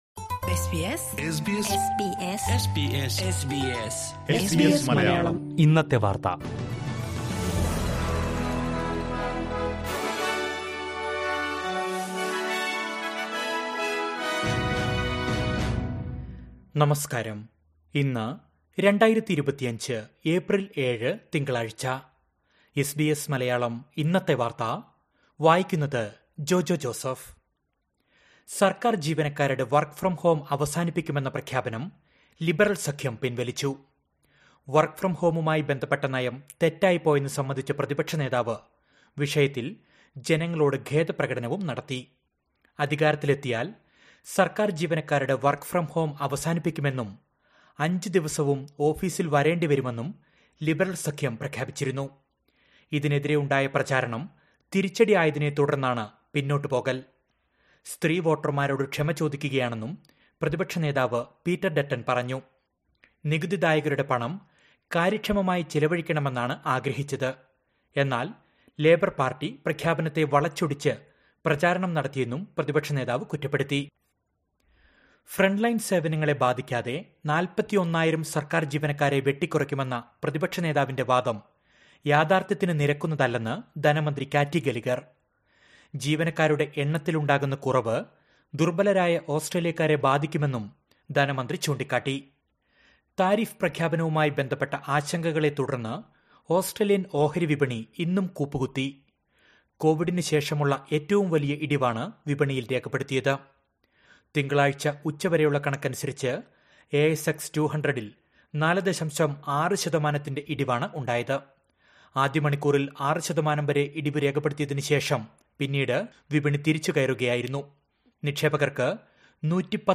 2025 ഏപ്രിൽ ഏഴിലെ ഓസ്‌ട്രേലിയയിലെ ഏറ്റവും പ്രധാന വാര്‍ത്തകള്‍ കേള്‍ക്കാം...